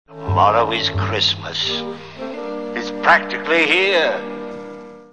Computer Sounds